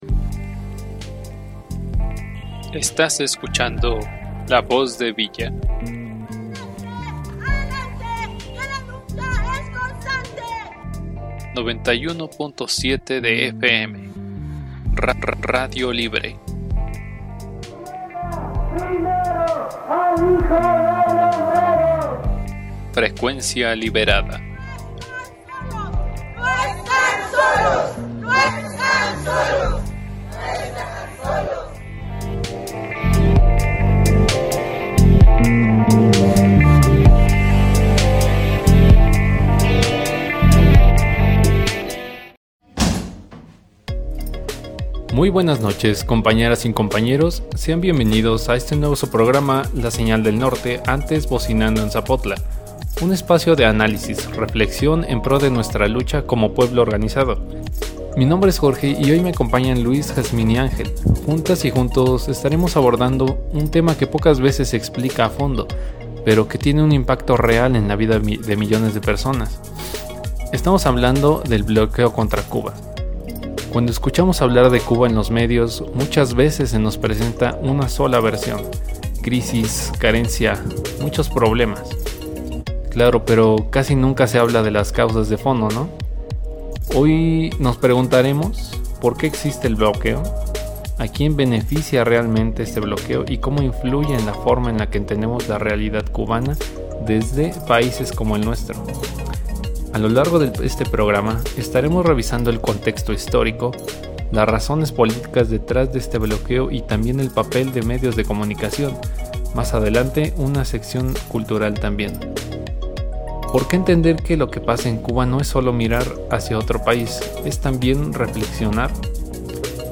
La comisión de comunicación de La División del Norte lleva a la comunidad una platica llena de información, contexto histórico, causas y consecuencias del injusto bloqueo mundial por parte de los gobiernos imperialistas hacia el pueblo de Cuba.